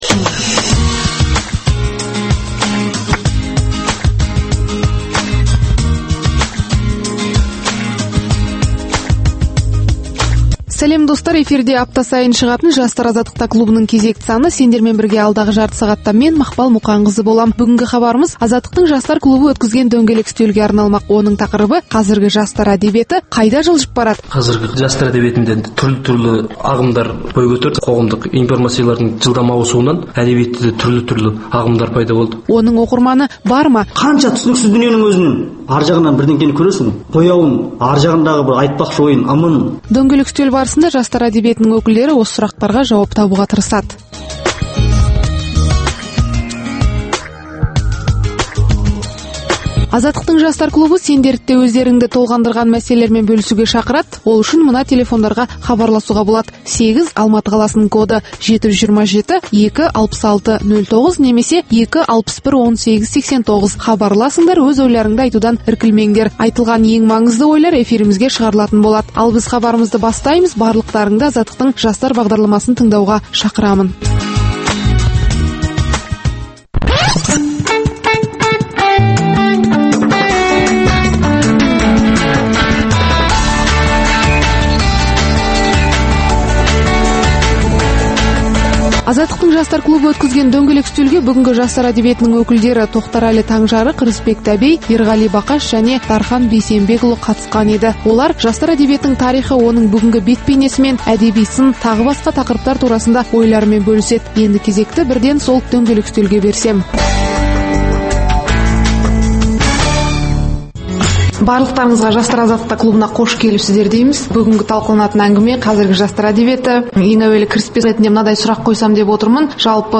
Пікірталас клубы